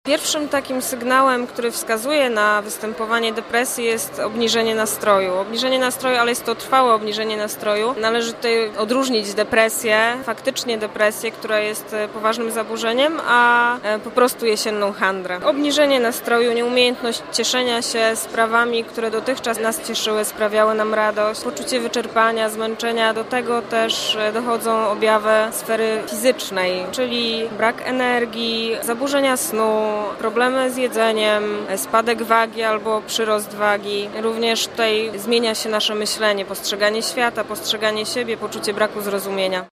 Jak się okazuje w pomocy osobom, których dotknęła ta przypadłością, ważne jest wyczulenie na sygnały, które te osoby wysyłają przez dłuższy czas. Mówi psycholog